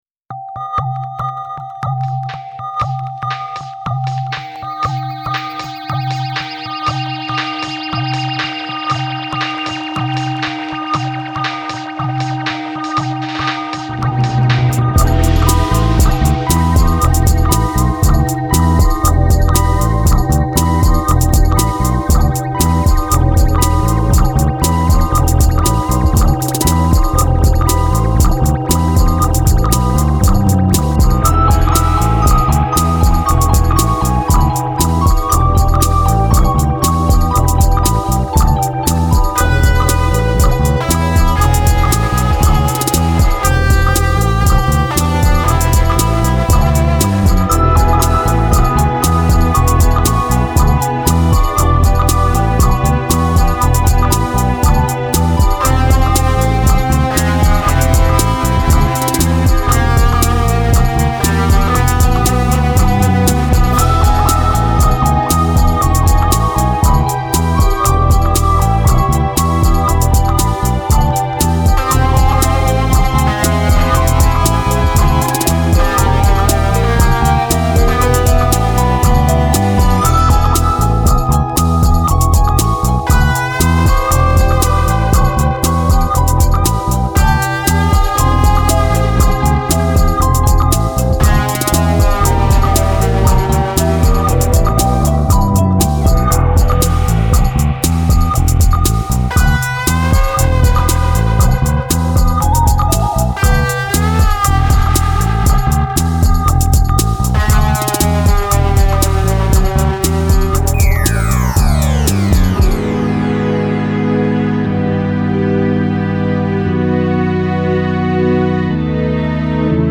Genre: DowntempoTrip-Hop.